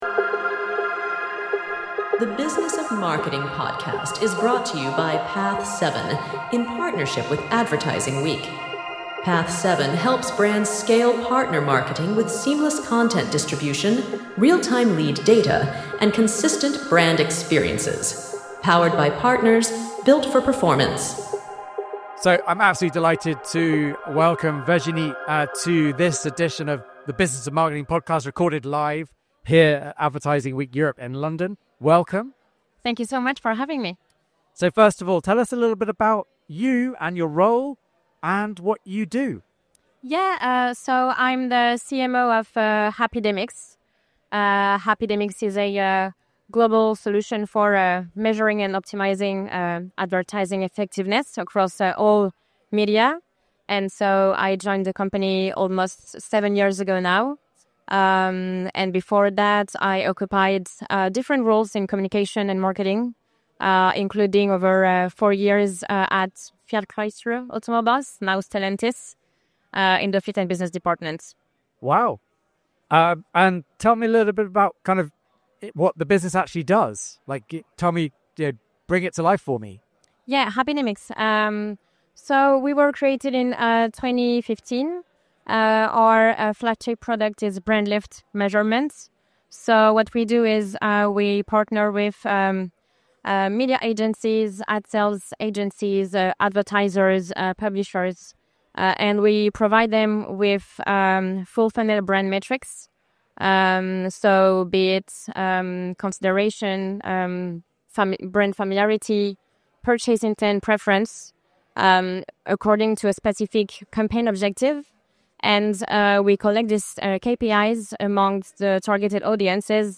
recorded live here at Advertising Week Europe in London